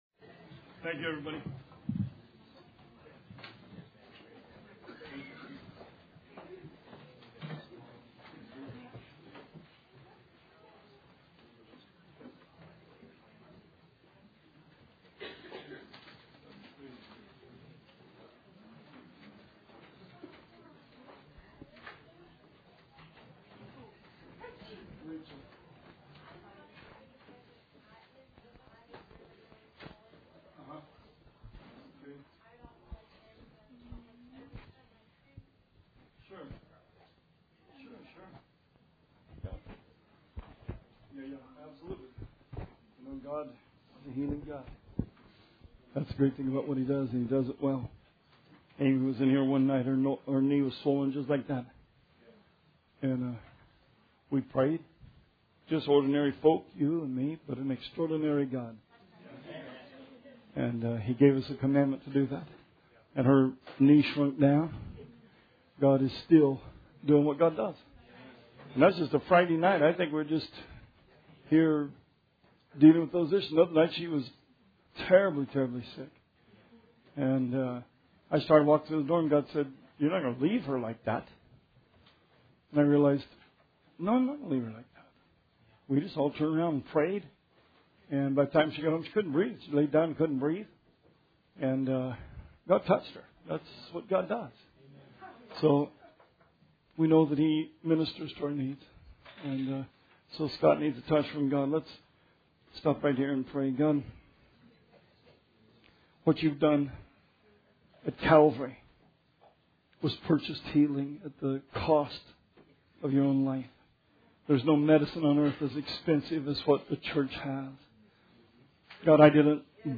Sermon 10/2/16